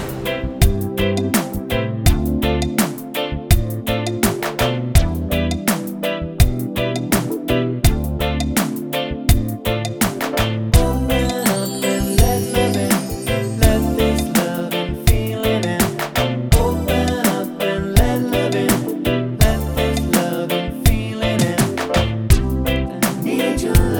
Version 1 Reggae 3:55 Buy £1.50